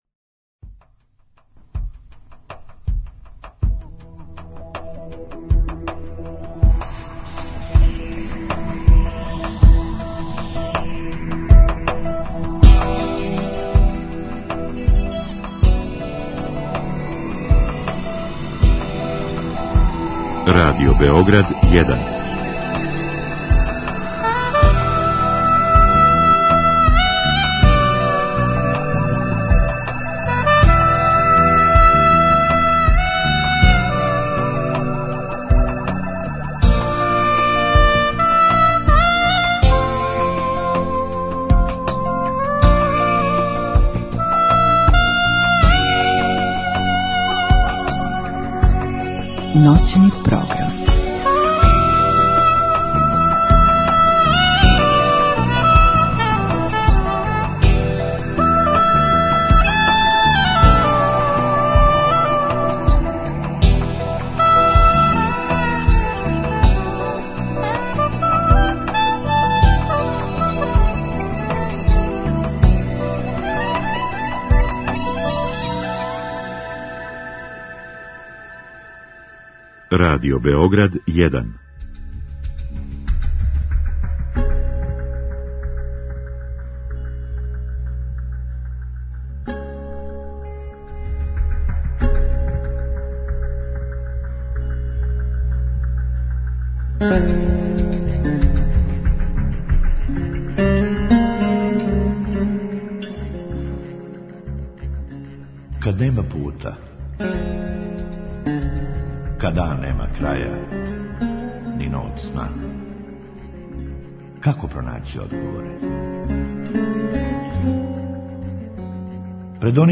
У другом сату отварамо телефонске линије за слушаоце, који у програму могу да поставе питања гошћи.